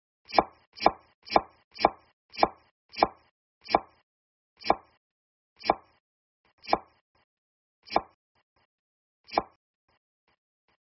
Tiếng Cắt, Thái… bằng dao (nhiều tiếng, chậm dần)
Thể loại: Tiếng ăn uống
Description: Tiếng cắt, thái chặt, băm, xắt, gọt… bằng dao vang lên “cạch, cạch, cạch…” rồi chậm dần thành “cạch… cạch…”, tạo cảm giác nhịp điệu giảm tốc. Âm thanh này thường dùng trong dựng phim hay chỉnh sửa video để tăng hiệu ứng chân thực, kết hợp với tiếng dao chạm thớt, tiếng lưỡi dao sượt qua bề mặt, gợi hình ảnh người đầu bếp đang tập trung, chuyển động từ nhanh sang chậm, tạo cảm giác hồi hộp hoặc kết thúc một phân đoạn.
tieng-cat-thai-bang-dao-nhieu-tieng-cham-dan-www_tiengdong_com.mp3